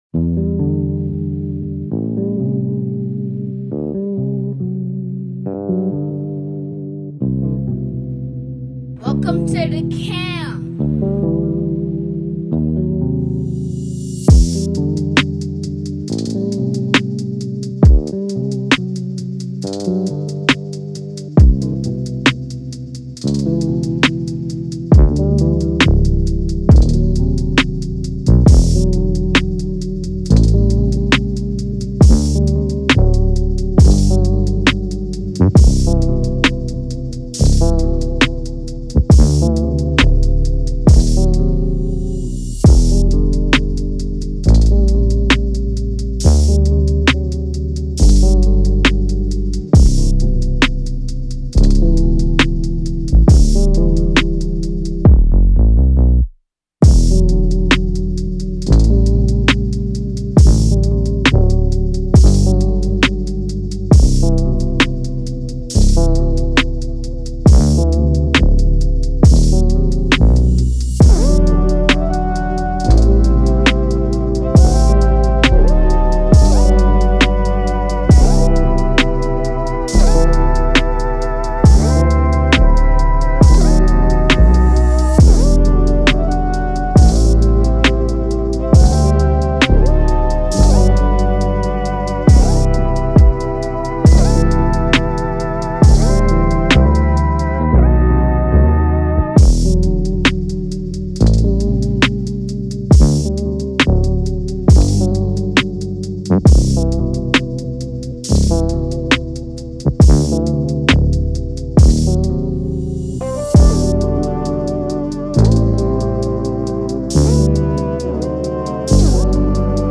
2024 in Official Instrumentals , R&B Instrumentals